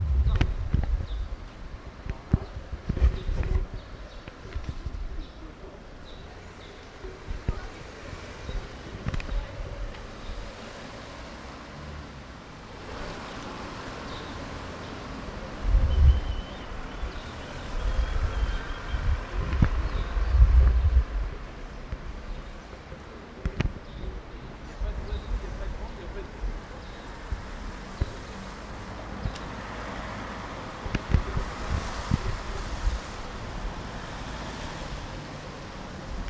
Camion Pompier devant Quick à Limoges rue jean jaures